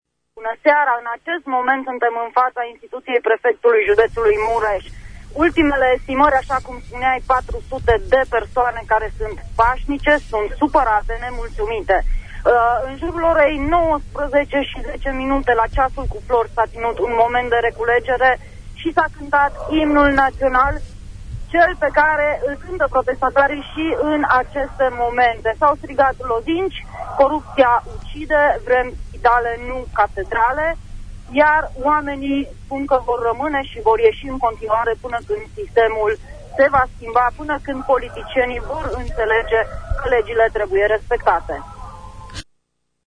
În acest moment protestatarii  mureșeni se întreaptă către căminele studențești scandând lozinci.